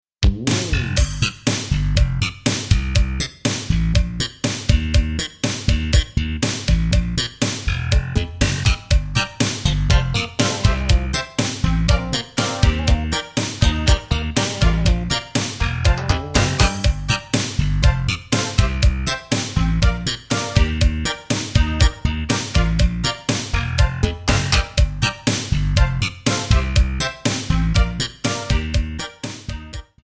Backing track Karaoke
Pop, 1980s